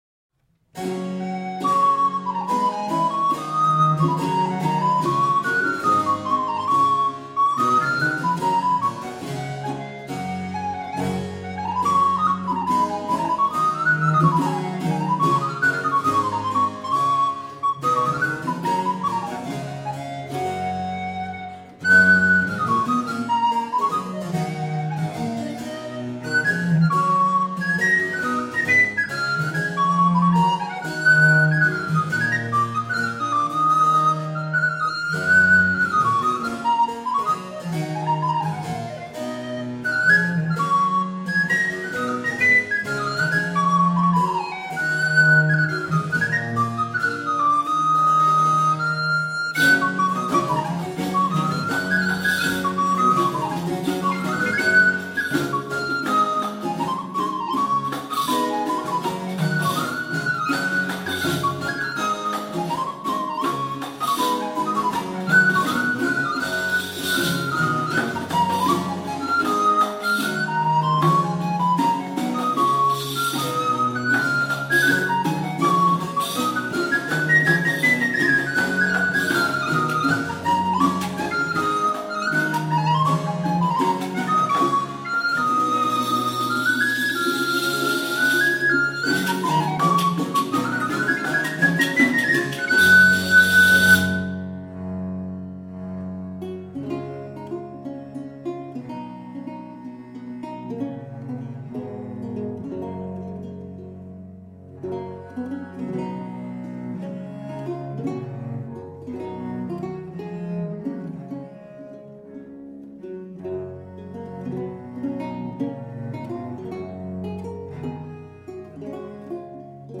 baroque violins
harpsichord